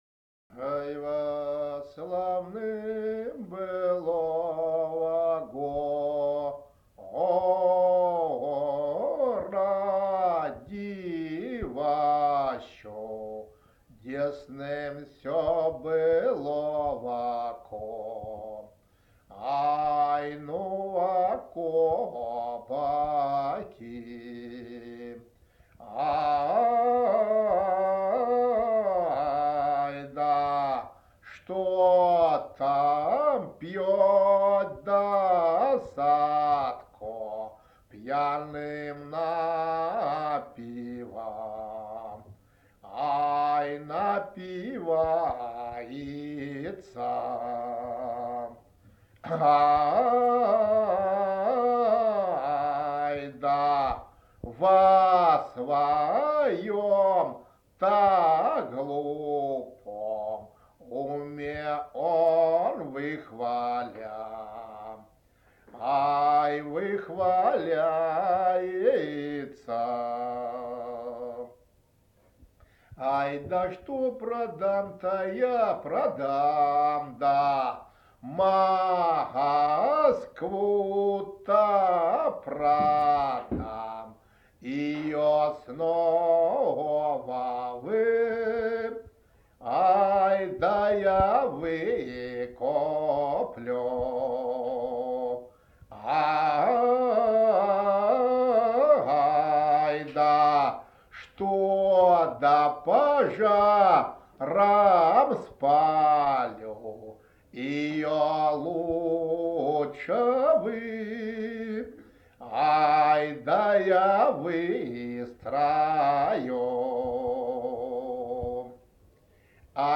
01 Былинная песня «Ай
Место фиксации: город Санкт-Петербург Год